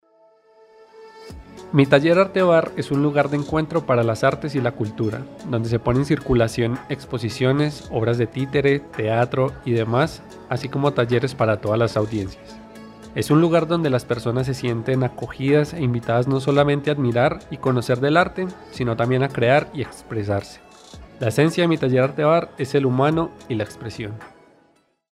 Voz en off